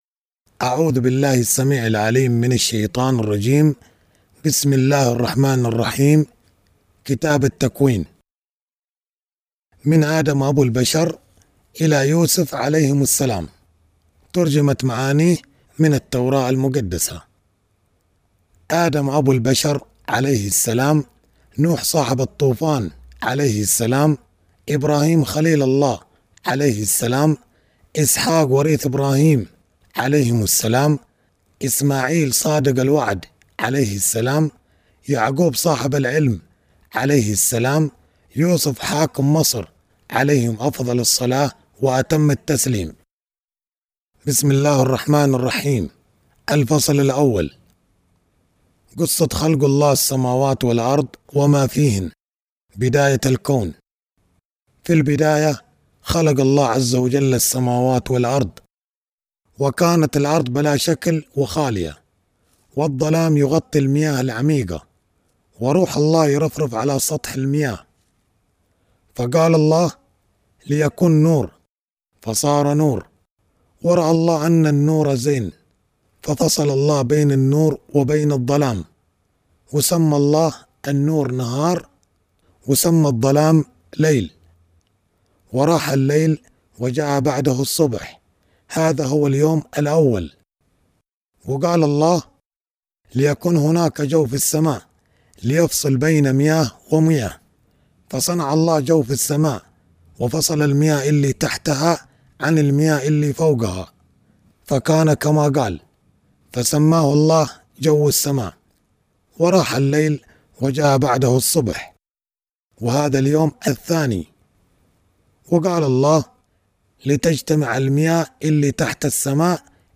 قصة أدم أبو البشر | قصص الأنبياء باللهجة الحضرمية